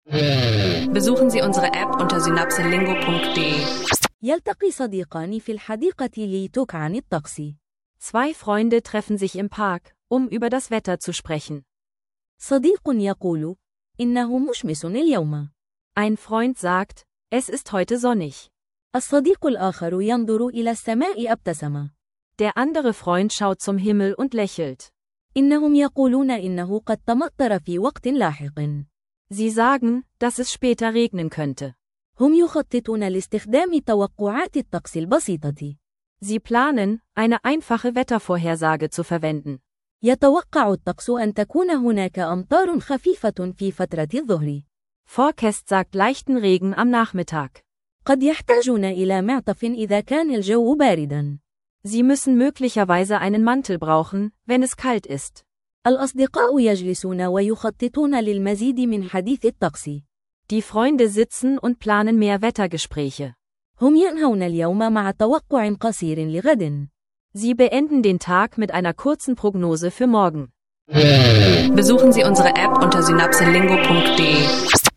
Zwei Freunde üben einfache Wetterphrasen und kurze Vorhersagen – praxisnahes Arabisch lernen mit SynapseLingo